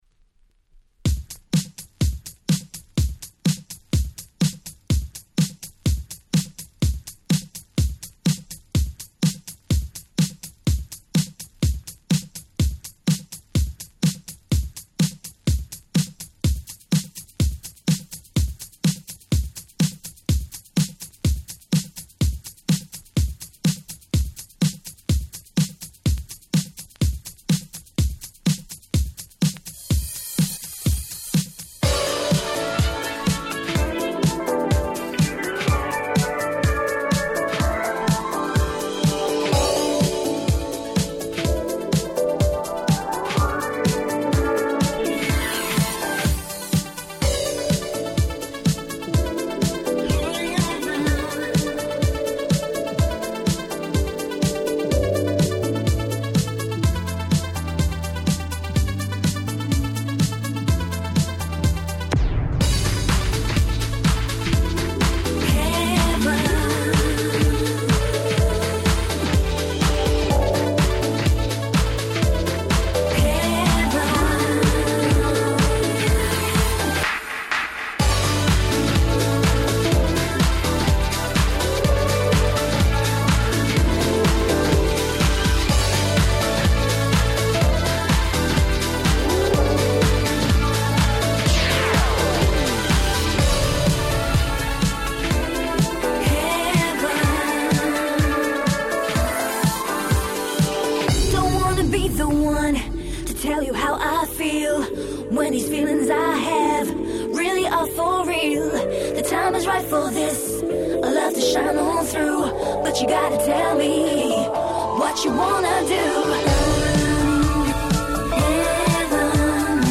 03' Nice Vocal House !!
キャッチーで非常に馴染み易く、R&Bファンにも自信を持ってオススメ出来ます！